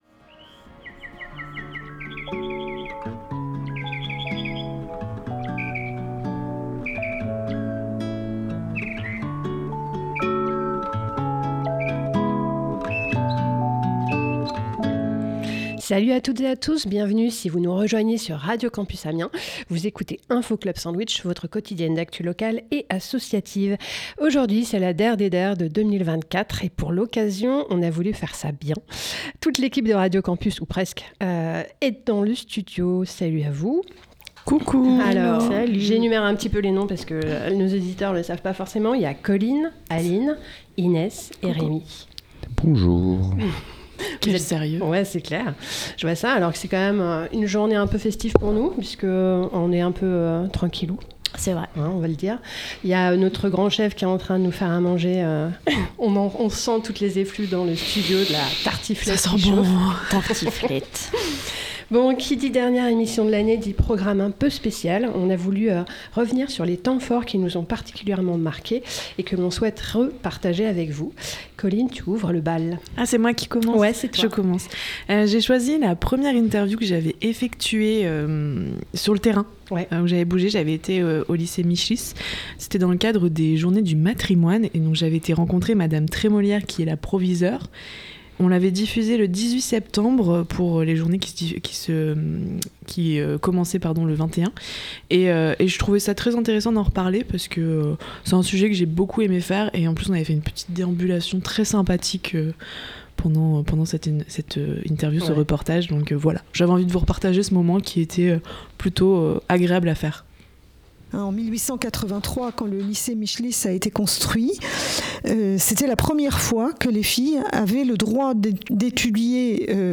Pour la dernière de l’année 2024, toute l’équipe de Radio Campus Amiens vous a préparé un best-of de nos temps forts. On revient sur des interviews, des émissions et même des génériques !
On n’oublie pas les musiques spéciales de noël pour fêter avec vous ce réveillon !